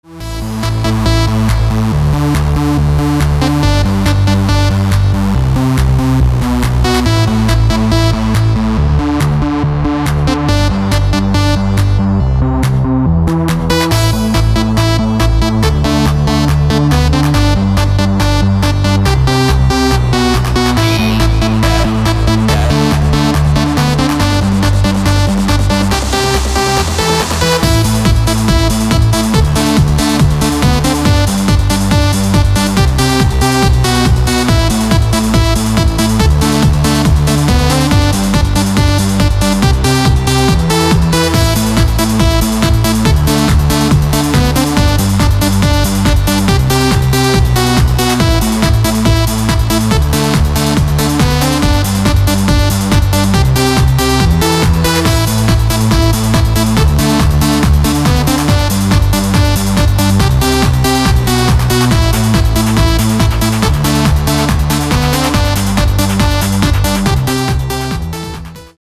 • Качество: 224, Stereo
ритмичные
громкие
dance
Electronic
EDM
электронная музыка
club
Trance
Tech Trance